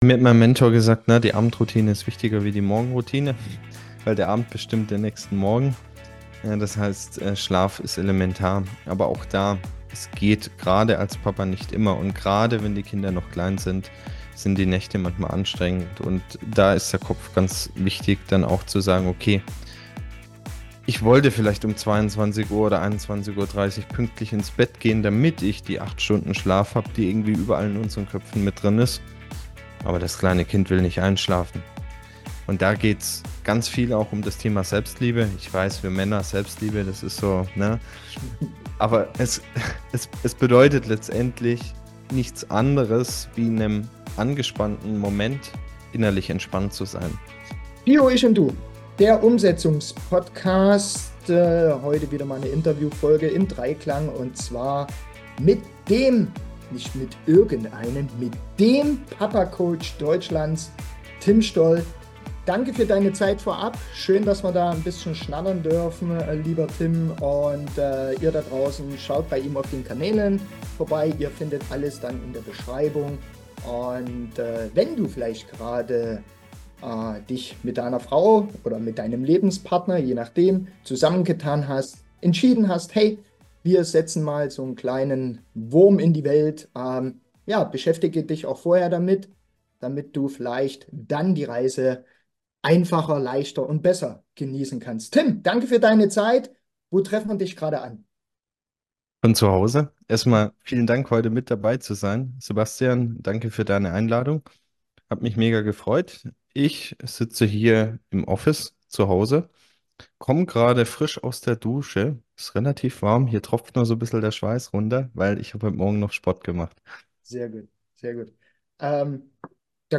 Interviewreihe